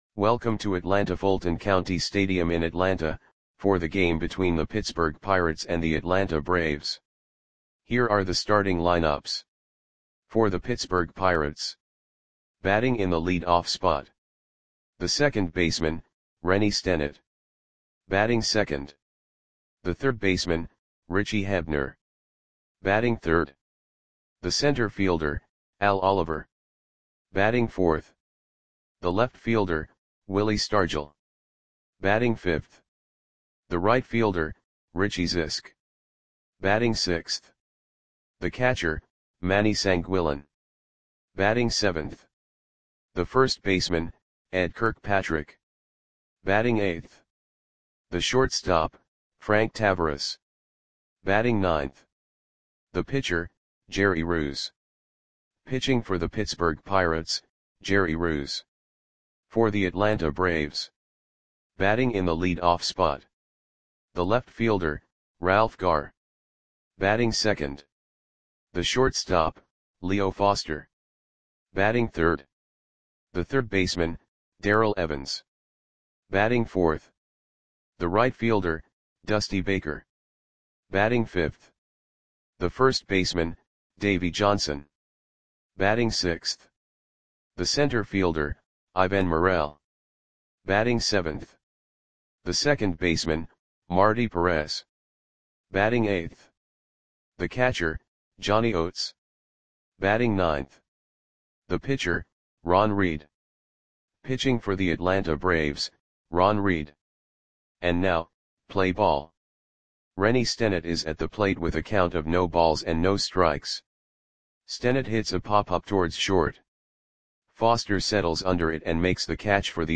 Audio Play-by-Play for Atlanta Braves on July 18, 1974
Click the button below to listen to the audio play-by-play.